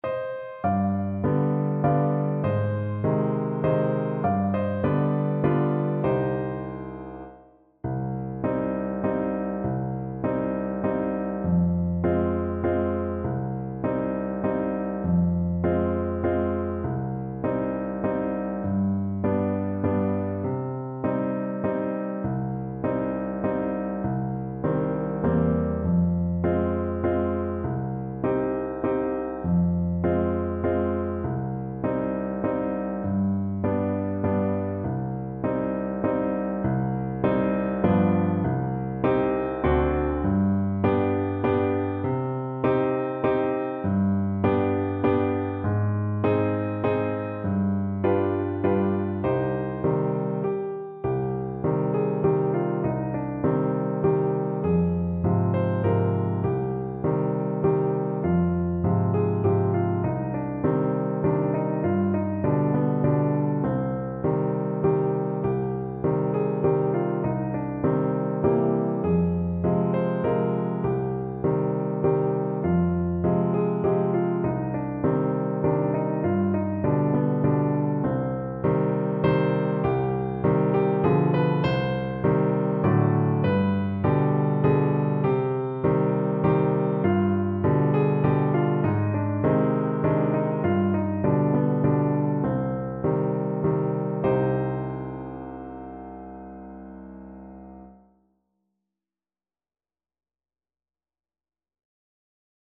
Play (or use space bar on your keyboard) Pause Music Playalong - Piano Accompaniment Playalong Band Accompaniment not yet available transpose reset tempo print settings full screen
Clarinet
C minor (Sounding Pitch) D minor (Clarinet in Bb) (View more C minor Music for Clarinet )
3/4 (View more 3/4 Music)
Moderato =c.100
Traditional (View more Traditional Clarinet Music)